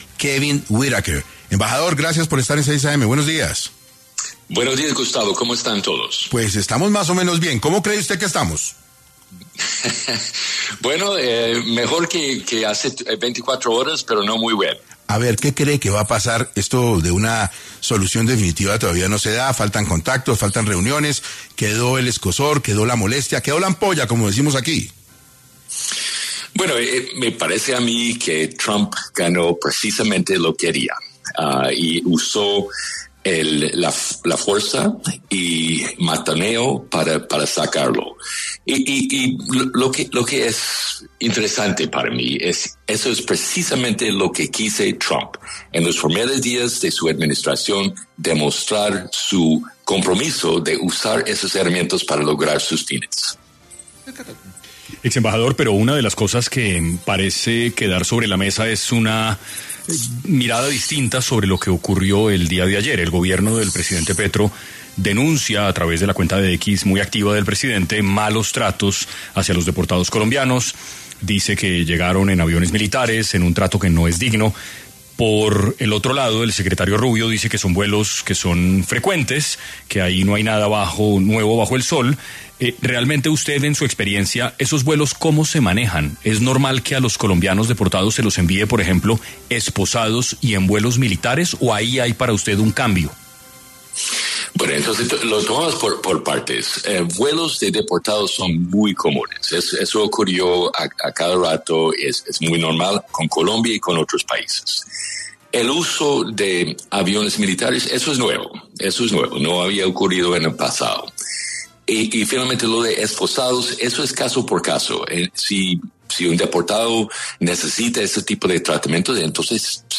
Luego de los enfrentamientos que sostuvieron Donald Trump y Gustavo Petro el pasado domingo, 26 de enero de 2025, debido a las duras decisiones sobre las personas deportadas, visados y aranceles, Kevin Whitaker. Exembajador de EE. UU. en Colombia, se conectó al programa 6AM del 27 de enero, para hablar sobre como esta situación va a afectar en el futuro.